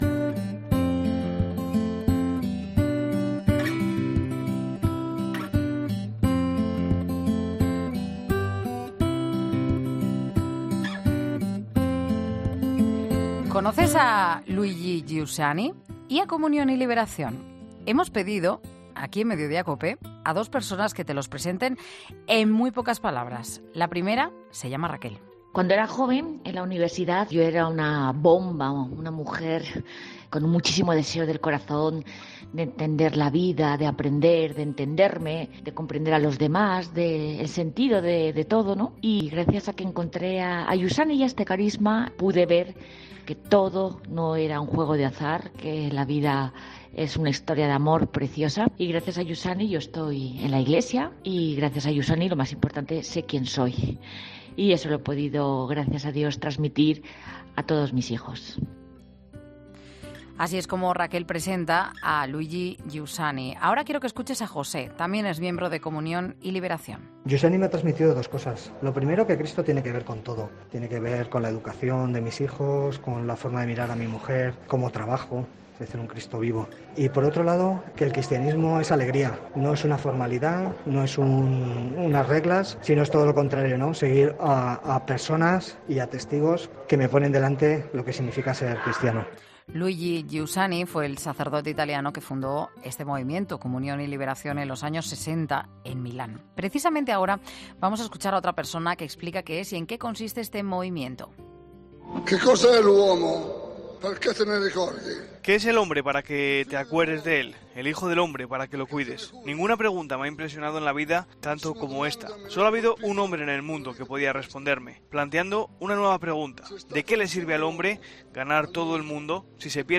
Hablamos con uno de los 3.000 voluntarios presentes en el 'Meeting de Rimini', de Comunión y Liberación